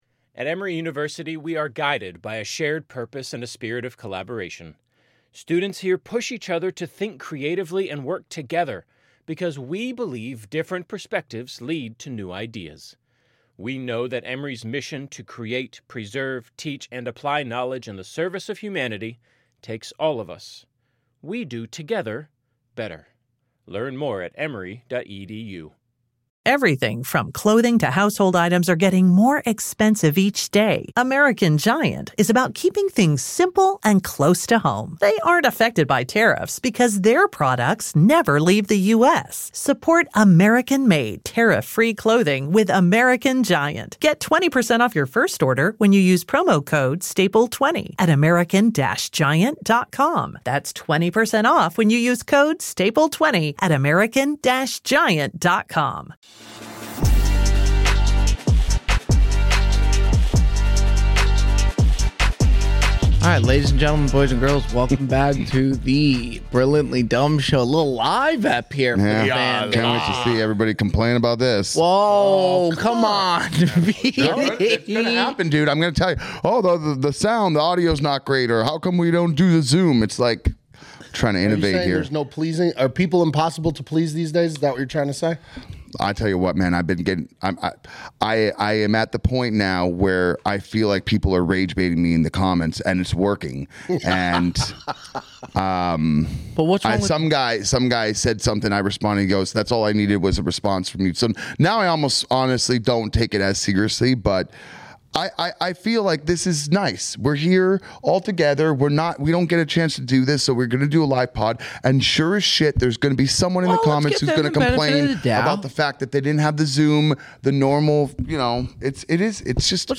Coming to you LIVE!